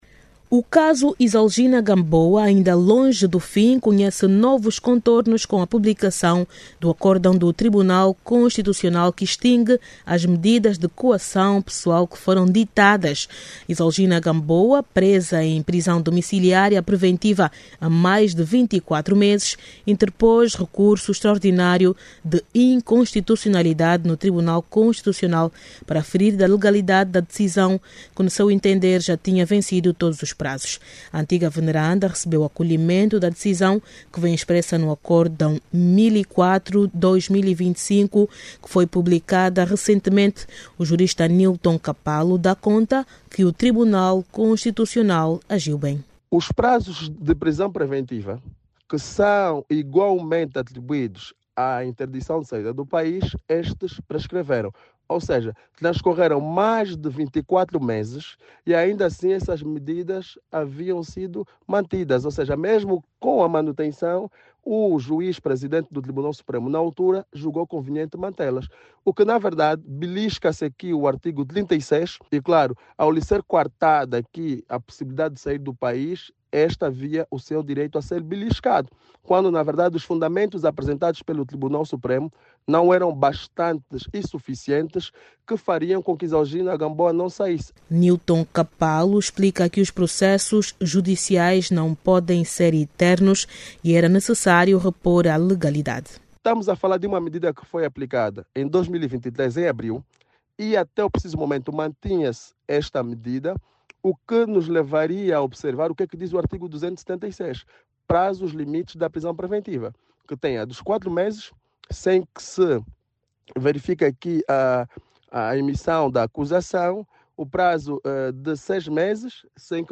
Por ordem do Constitucional a antiga presidente do Tribunal de Contas, vai responder em liberdade pelos crimes de que vem sendo investigada. Clique no áudio abaixo e ouça a reportagem